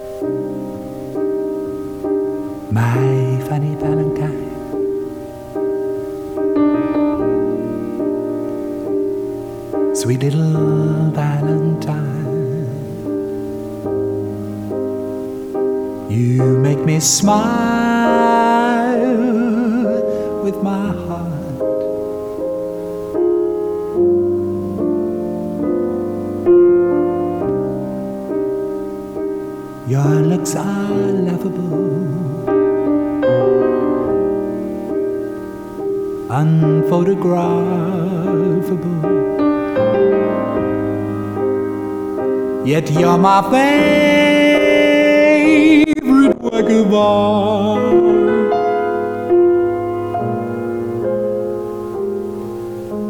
Charakterstimmen mit verschiedenen Tonlagen und Akzenten
Male